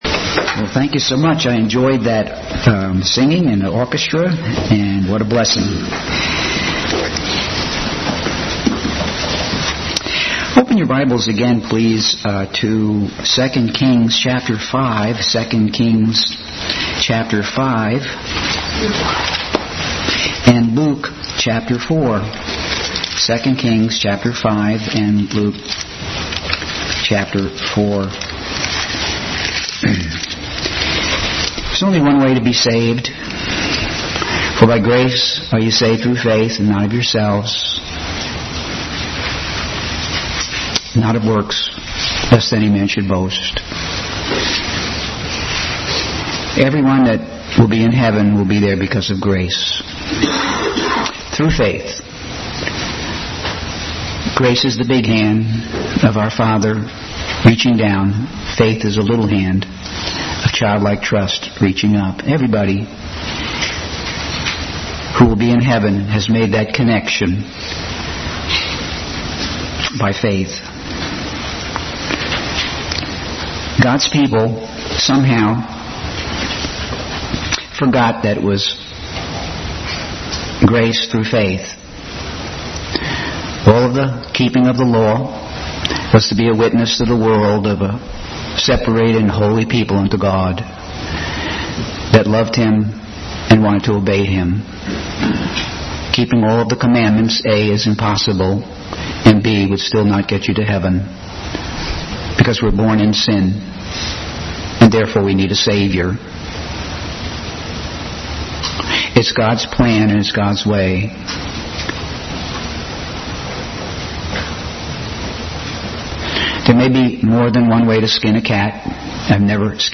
Passage: Luke 4:16-30, 2 Kings 5:1-19 Service Type: Family Bible Hour